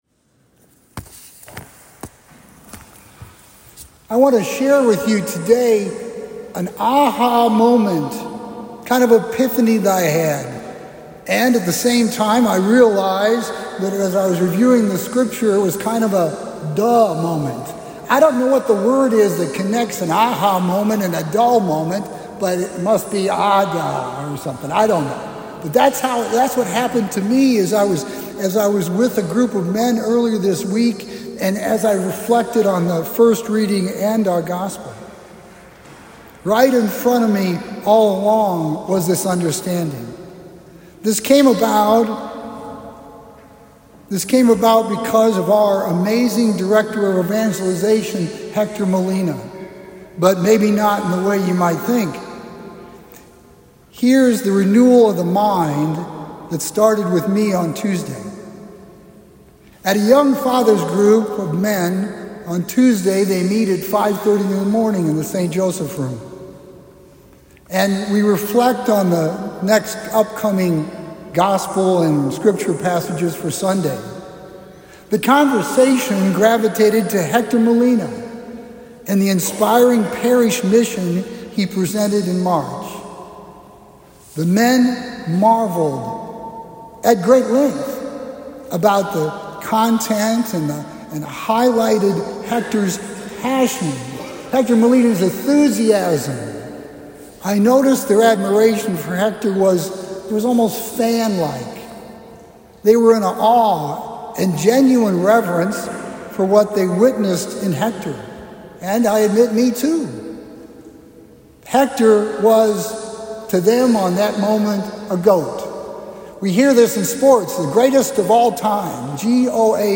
Homily – May 8, 2025